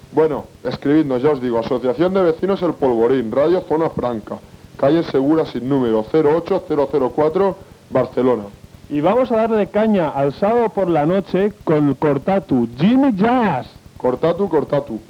Adreça i presentació d'un tema musical.